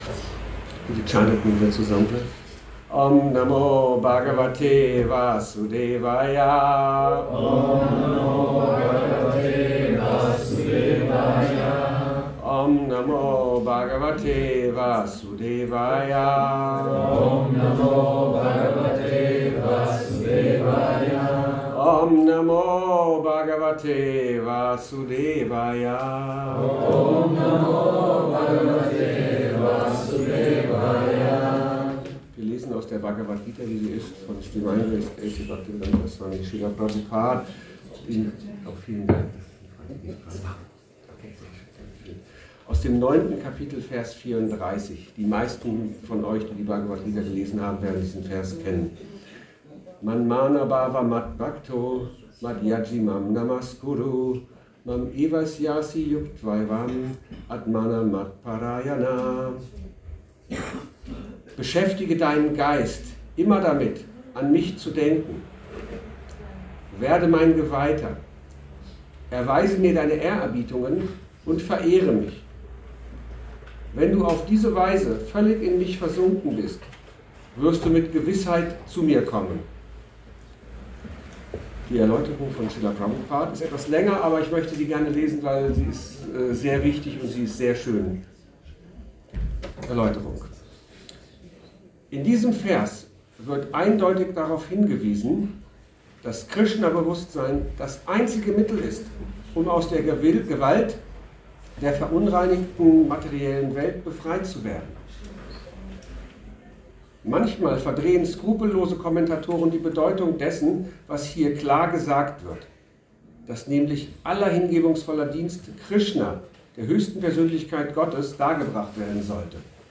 Wo ist unsere Zuflucht? – spiritueller Vortrag zu Bhagavad Gita 5.29